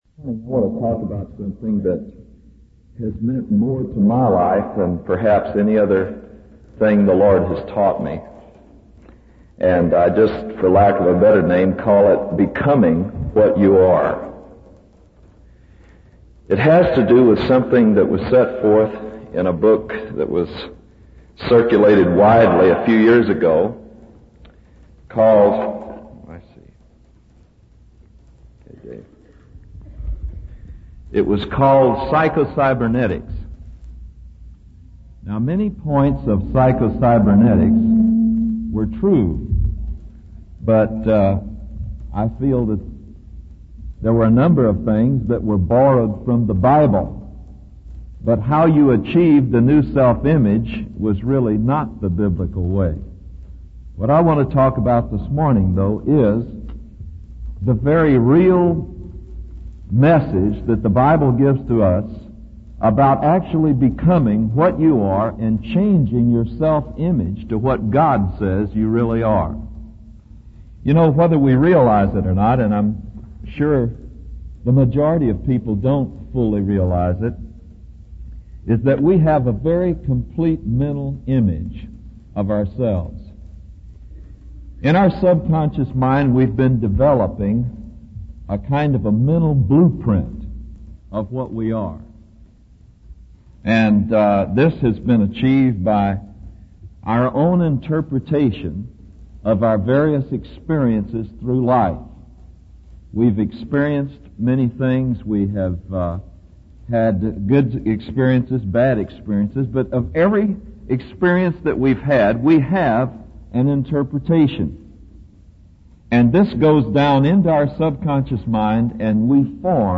In this sermon, the preacher uses an illustration from the book 'Psycho-Cybernetics' to explain how God wants to bring about a change in our lives.